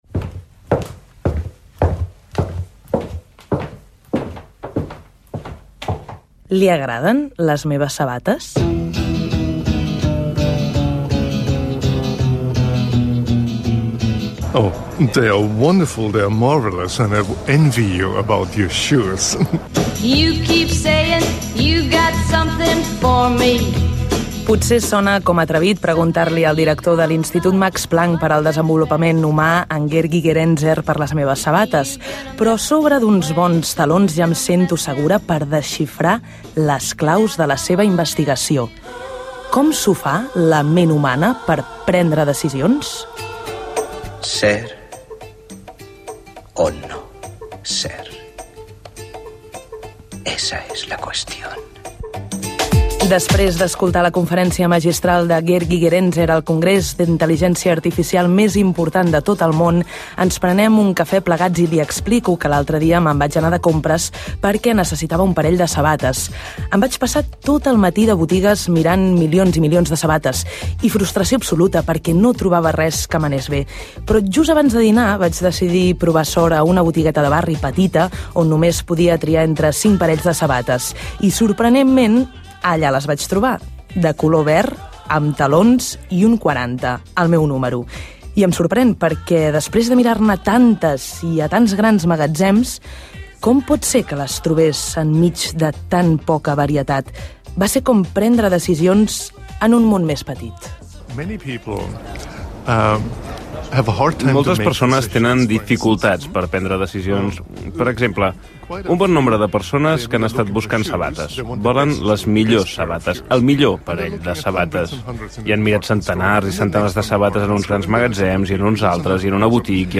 Com es prenen les decisions, amb una entrevista al psicòleg Gerd Gigerenzer
Divulgació
FM